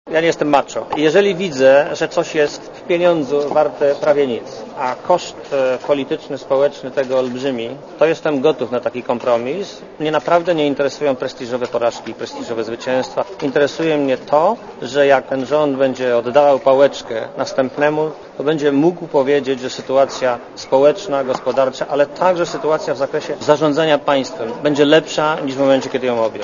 * Mówi premier Marek Belka (102kB)*